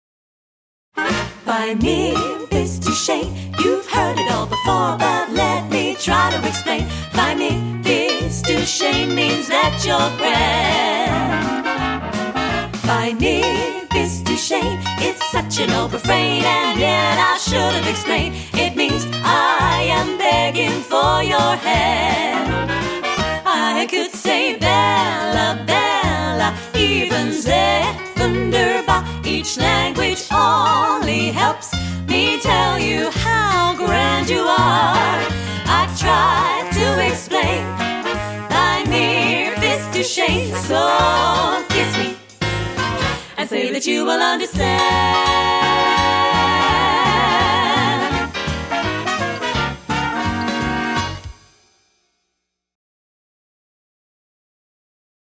Female Vocals x 3, Backing Tracks